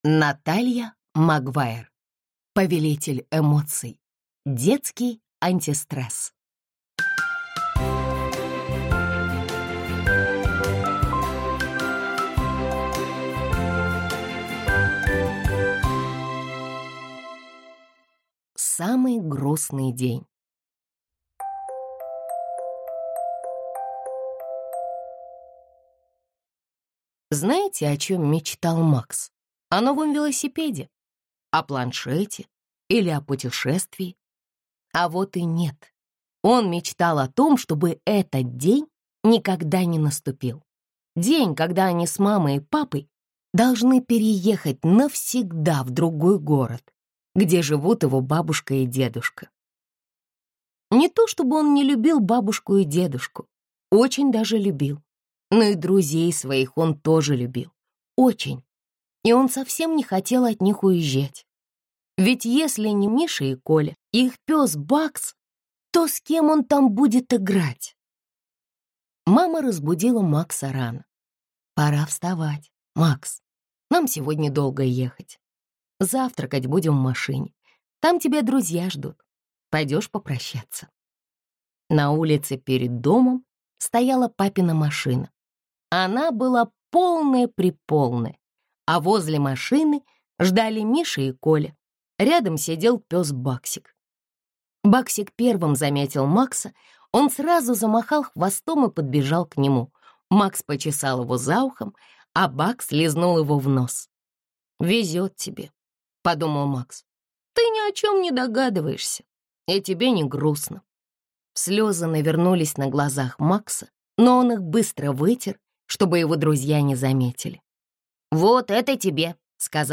Аудиокнига Повелитель эмоций. Детский антистресс | Библиотека аудиокниг